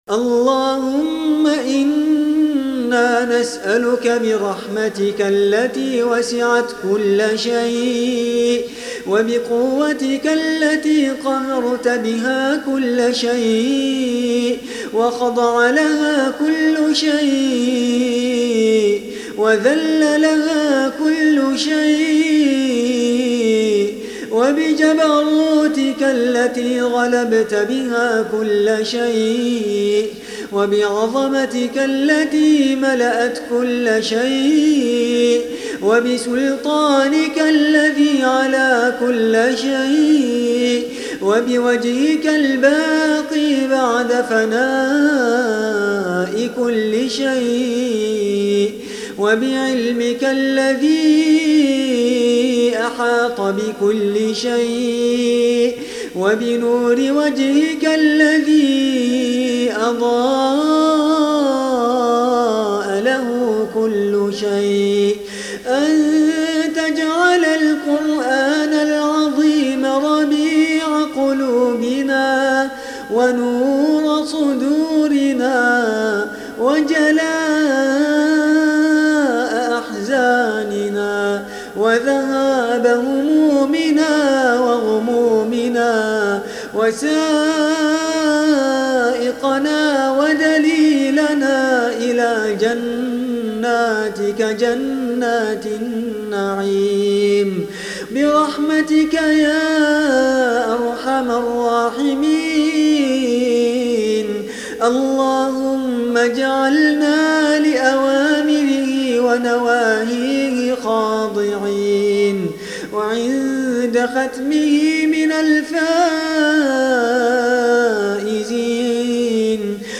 دعاء ختم القرآن
تسجيل لدعاء ختم القرآن الكريم